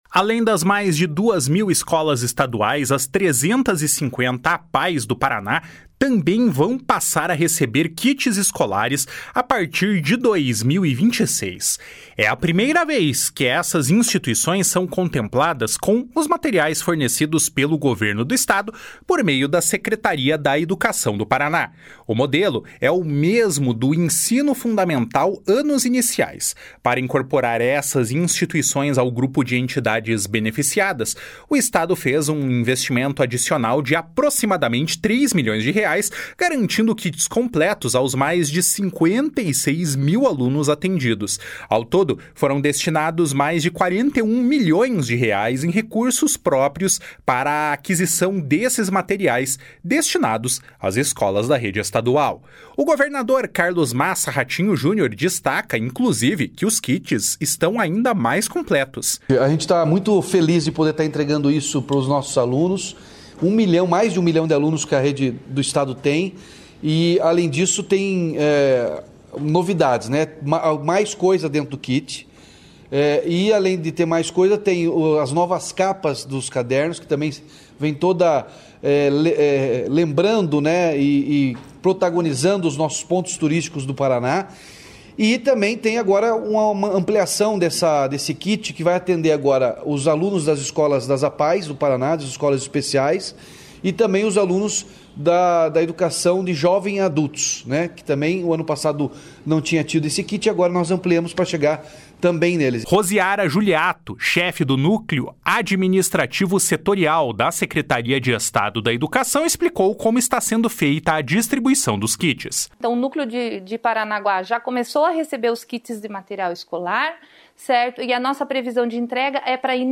O governador Carlos Massa Ratinho Junior destaca que os kits estão ainda mais completos. // SONORA RATINHO JUNIOR //
O secretário da Educação, Roni Miranda, acrescentou o custo que é aliviado das famílias com a medida.